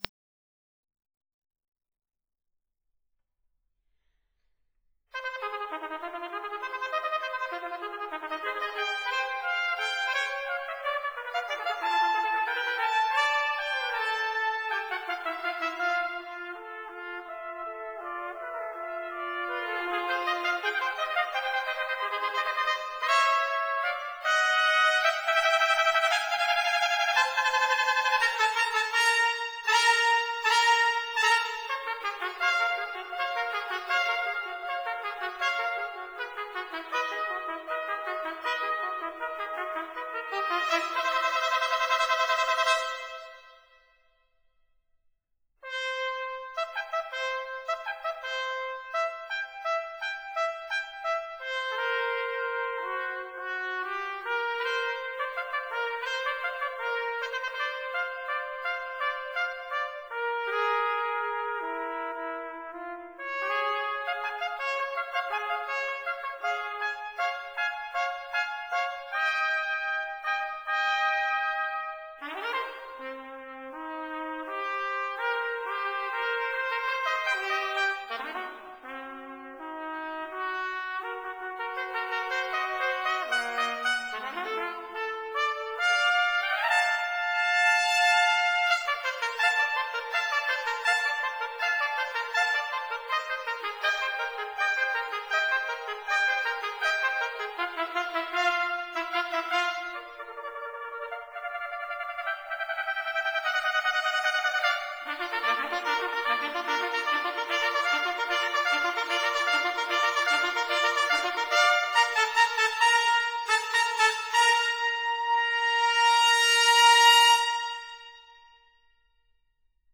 trumpets
horn
trombone
tuba
trombones